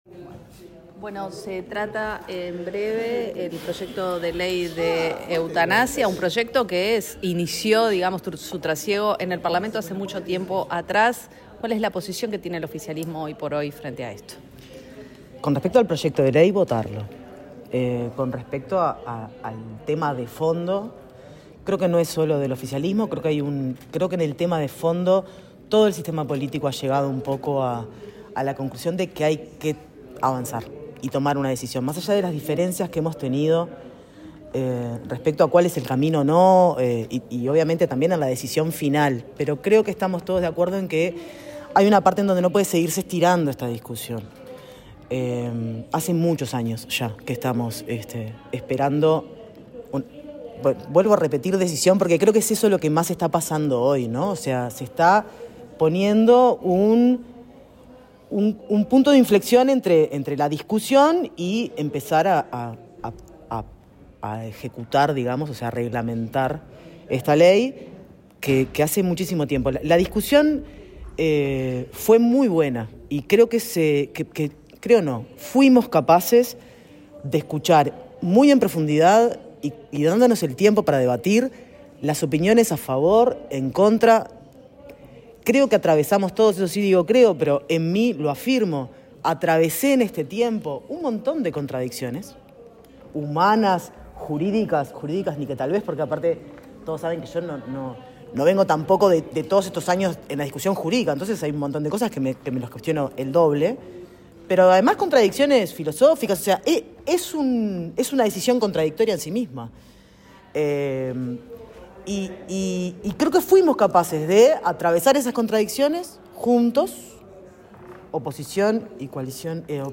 Audio de la senadora Patricia Kramer quien en ronda de prensa,mas temprano, también se refirió a este tema: